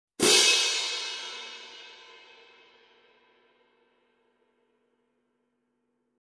Sabian 18" HHX New Symphonic Viennese Cymbals
Tonal richness and HHX warmth make for a musical pairing.
Loud Crash